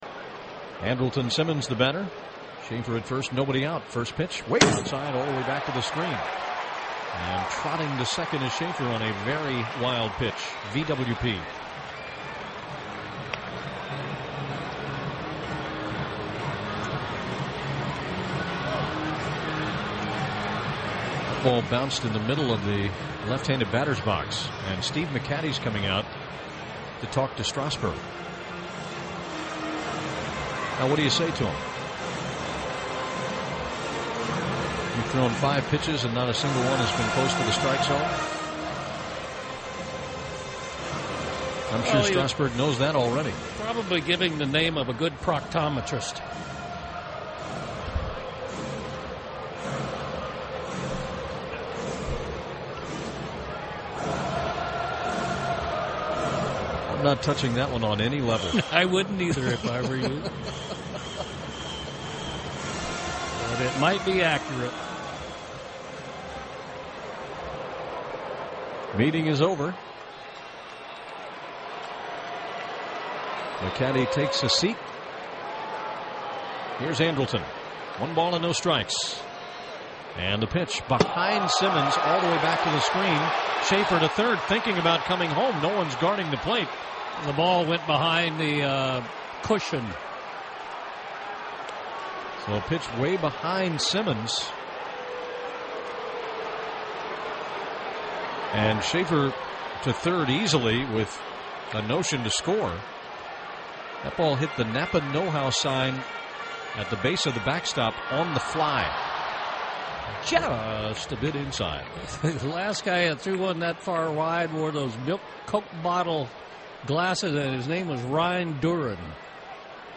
Part 1 of 3, Braves vs. Nationals at Turner Field.